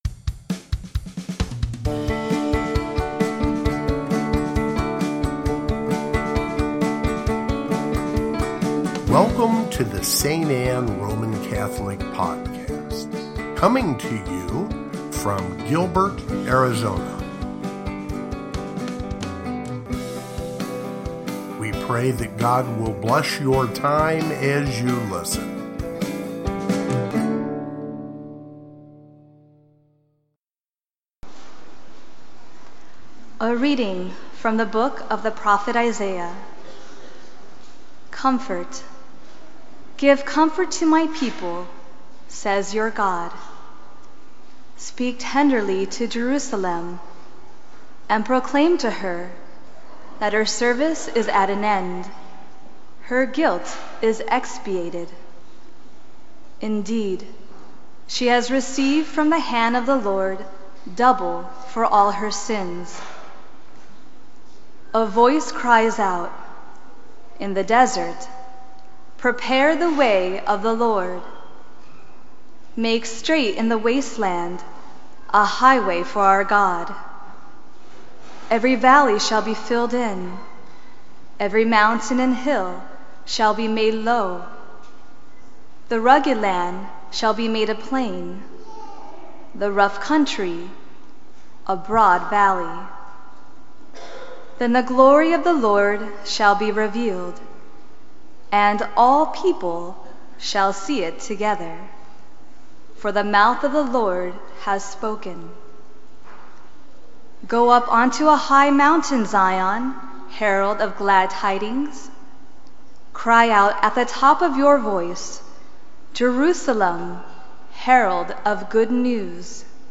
Second Sunday of Advent (Readings)
Gospel, Readings, Advent